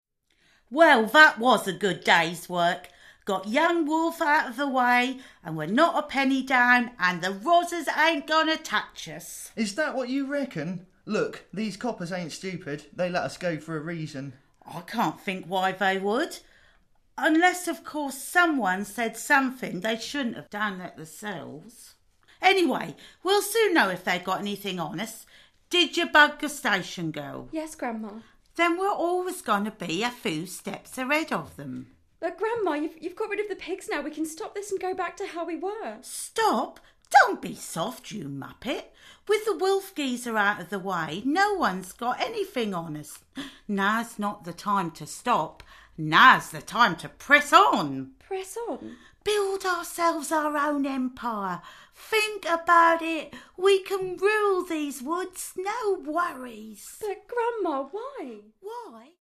Below are a number of extracts from the play to whet your whistles ...
ABOVE: The cast record the audio version of HAPPY EVER AFTER.